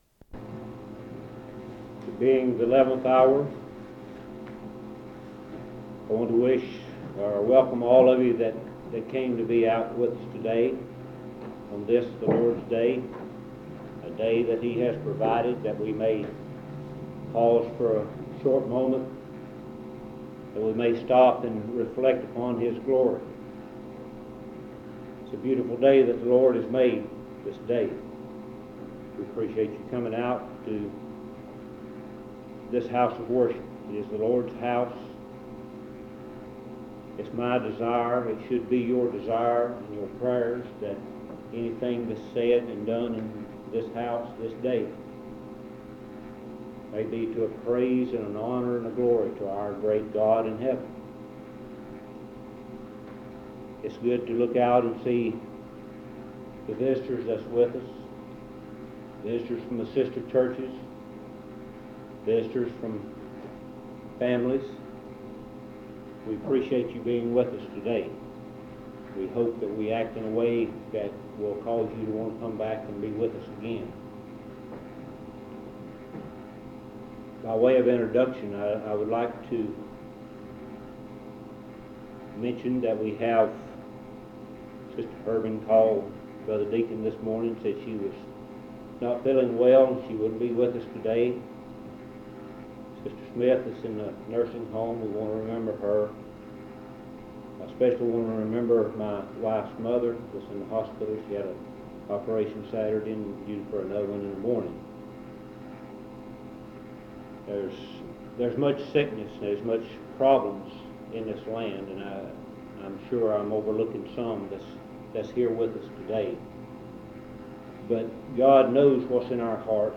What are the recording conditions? In Collection: Monticello Primitive Baptist Church audio recordings Thumbnail Title Date Uploaded Visibility Actions PBHLA-ACC.002_005-B-01.wav 2026-02-12 Download PBHLA-ACC.002_005-A-01.wav 2026-02-12 Download